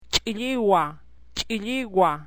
Glottalized Africate .. ch'